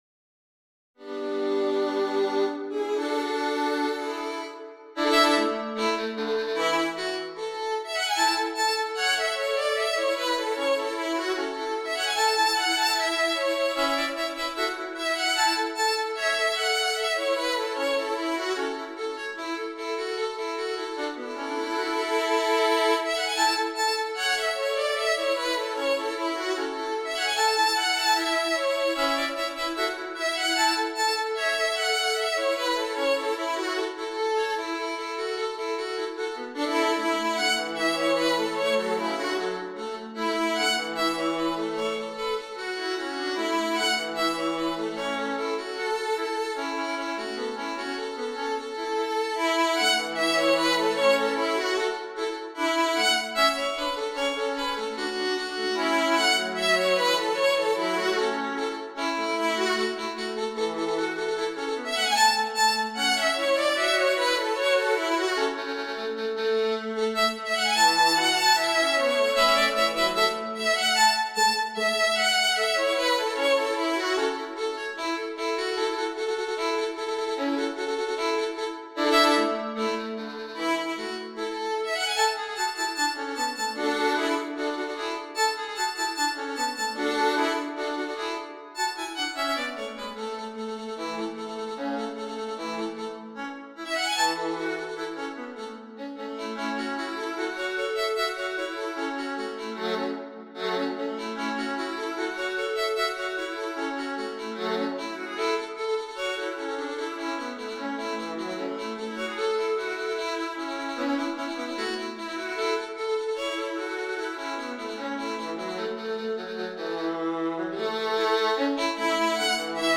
Among American fiddle tunes, this "reel" for dancing is a simple, repetitive structure.
3 pages, circa 2' 30" - an MP3 demo is here: